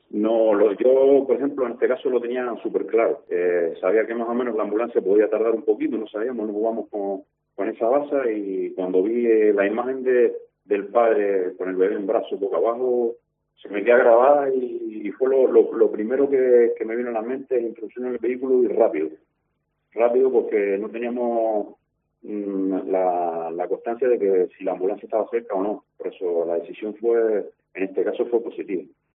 El agente relata en nuetsros micrófonos que el niño se atragantó por culpa de un coágulo de flema de leche con cereales, más el medicamento que había tomado.